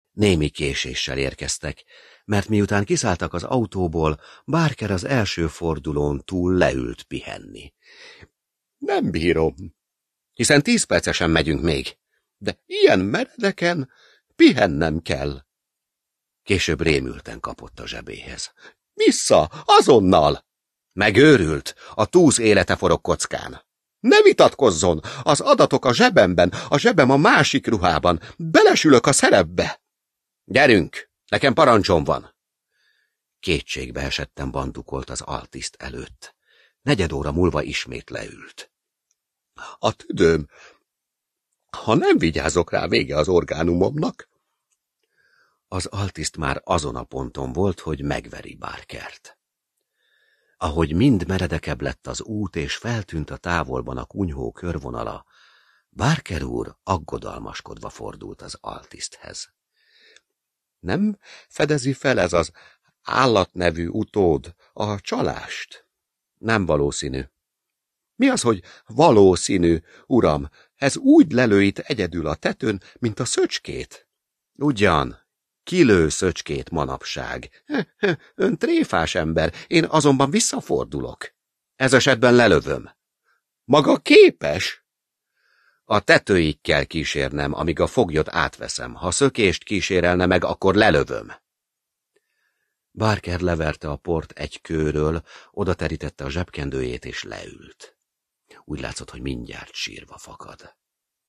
A hangoskönyv Kálid Artúr előadásában hallható.
Tigrisvér (Online hangoskönyv) Kálid Artúr előadásában Rejtő Jenő (P. Howard) Hallgass bele!
rejto_jeno_a_tigrisver_sample.aac